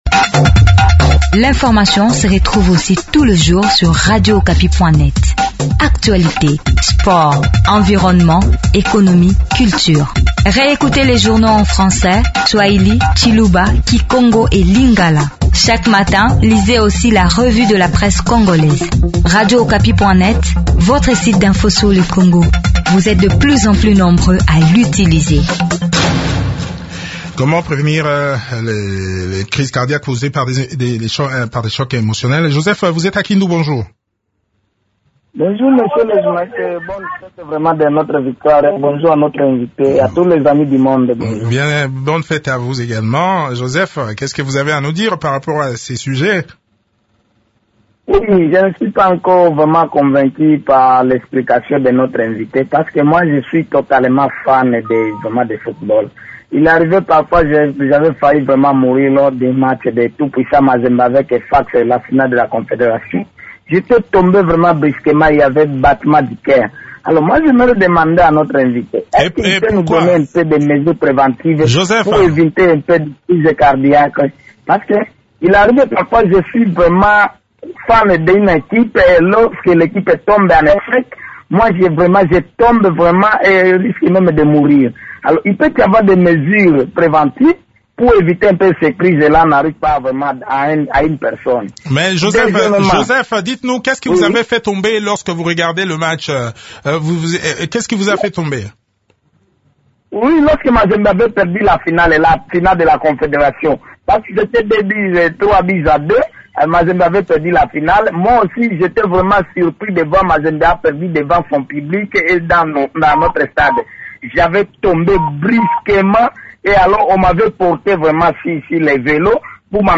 Les éléments de réponse dans cet entretien